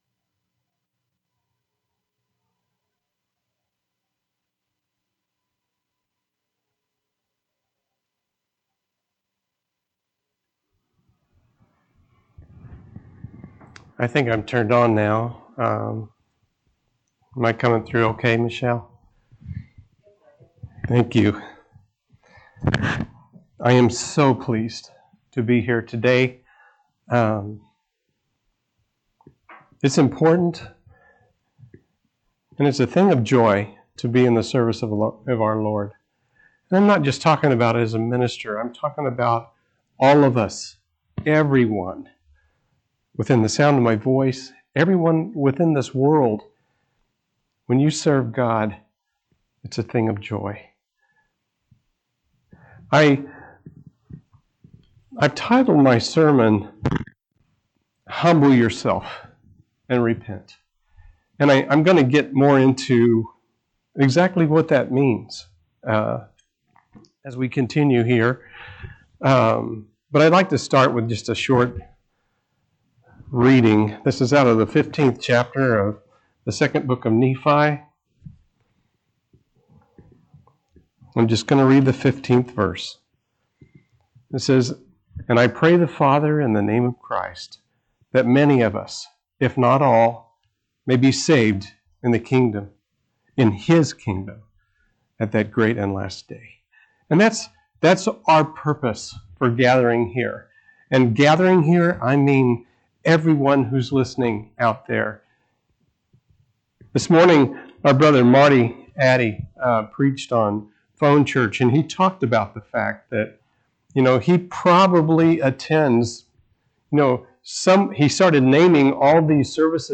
1/10/2021 Location: Phoenix Local Event